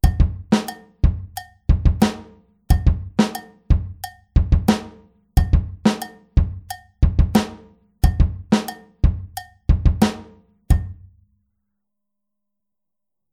Rechte Hand auf Kopfbecken (bell/head) oder Kuhglocke (cowbell)
Bei der 4tel-Variante spielt die rechte Hand nicht mehr auf dem HiHat sondern wegen des Punches auf dem Kopfbecken (bell/head aber nicht mit der Kuppe) oder der Kuhglocke (cowbell).